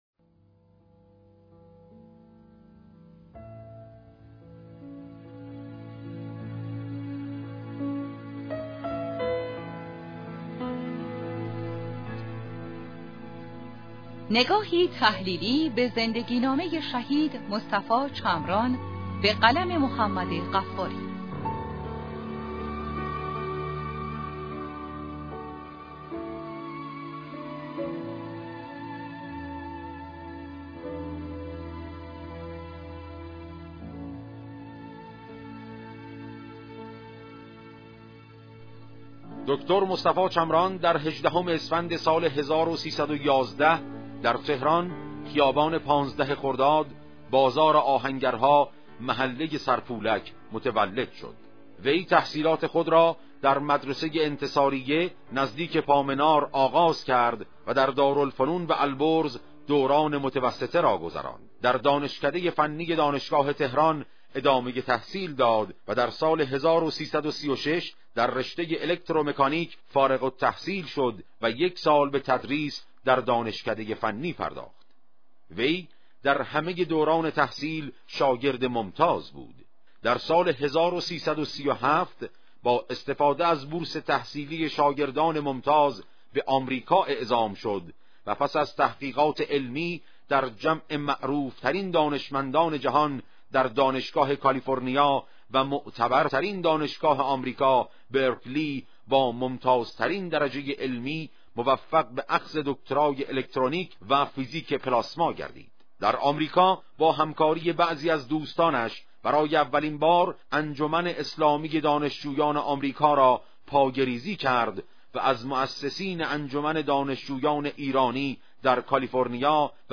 صوت روایتگری